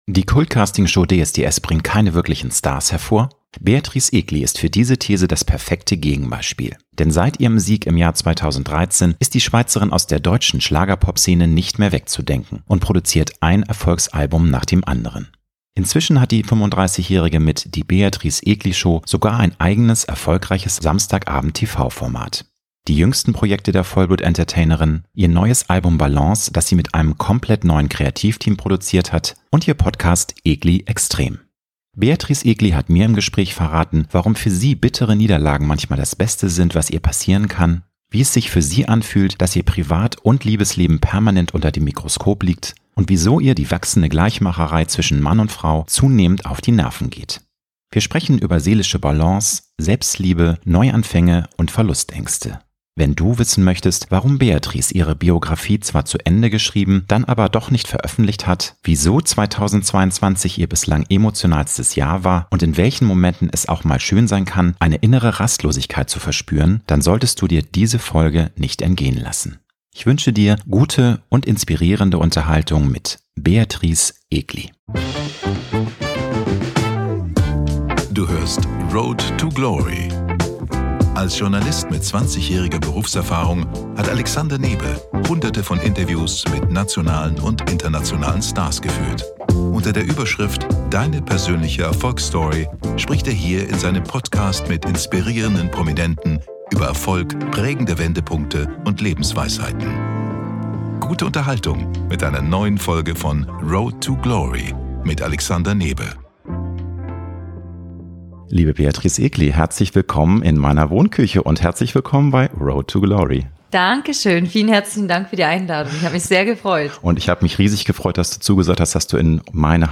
Beatrice Egli hat mir im Gespräch verraten, warum für sie bittere Niederlagen manchmal das Beste sind, was ihr passieren kann, wie es sich für sie anfühlt, dass Ihr Privat- und Liebesleben permanent unter dem Mikroskop liegt und wieso ihr die wachsende Gleichmacherei zwischen Mann und Frau zunehmend auf die Nerven geht. Wir sprechen über seelische Balance, Selbstliebe, Neuanfänge und Verlustängste.